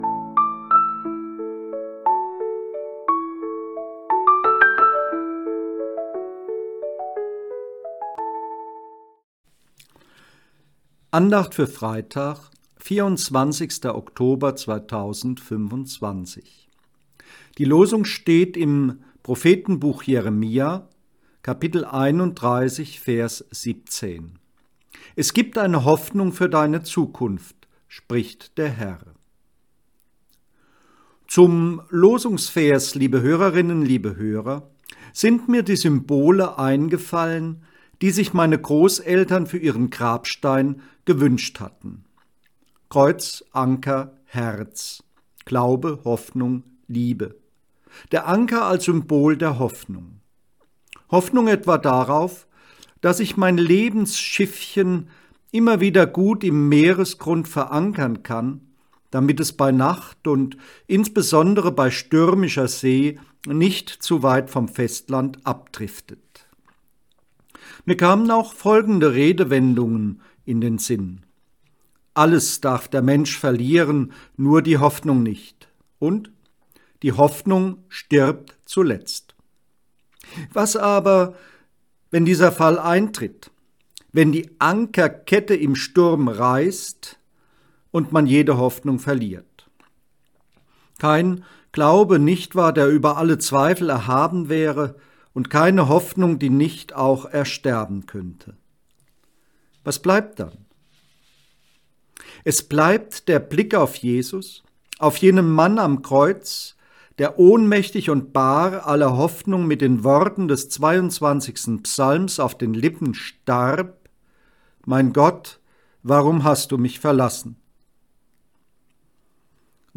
Losungsandacht für Freitag, 24.10.2025